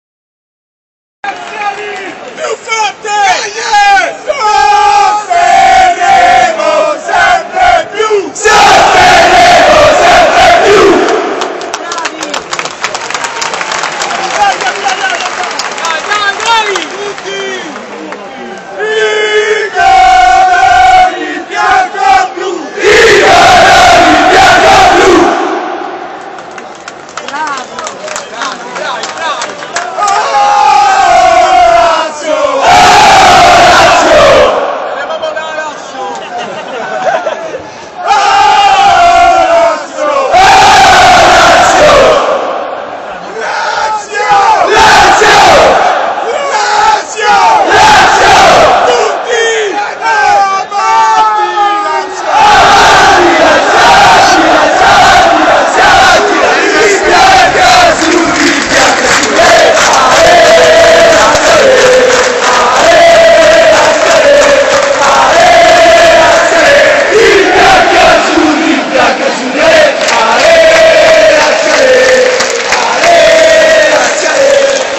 Qui troverete tutti i cori che si cantano allo Stadio.